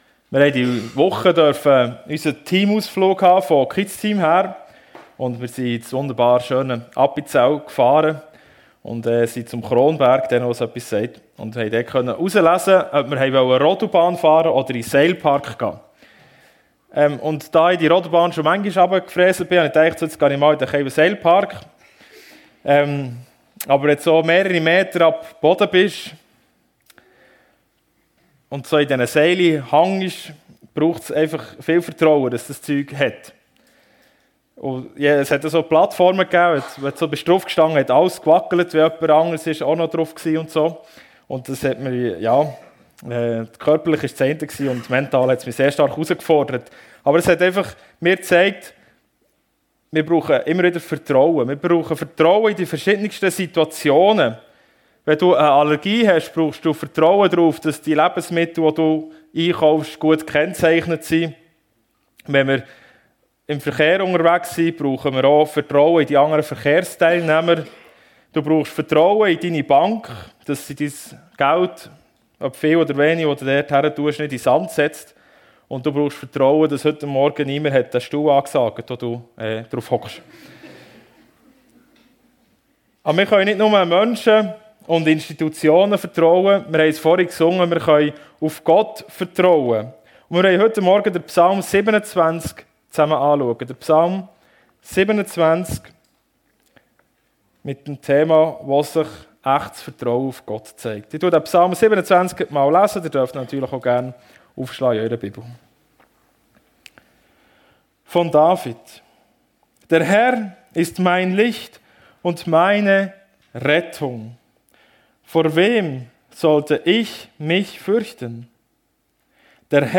Worin sich echtes Vertrauen auf Gott zeigt ~ FEG Sumiswald - Predigten Podcast